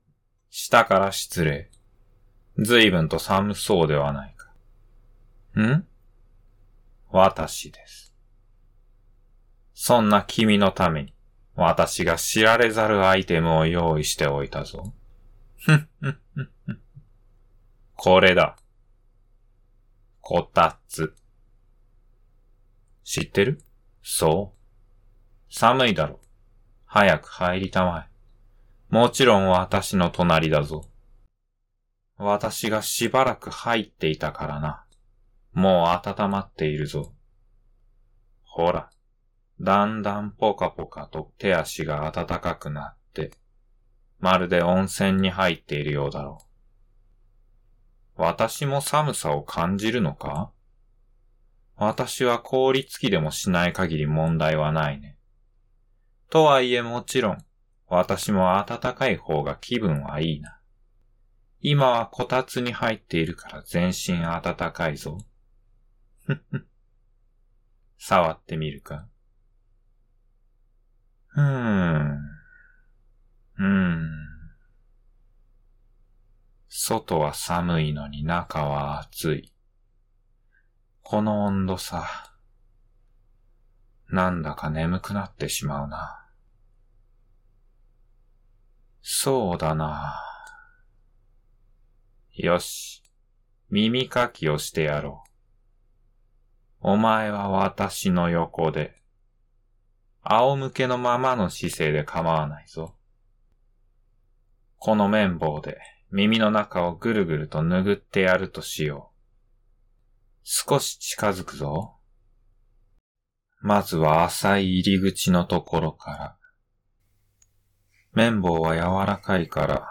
【眠くなる声】異形頭の貴族に耳かきしてもらう耳かきボイス -冬Ver- 【男性ボイス】
mimikaki_winter_full.mp3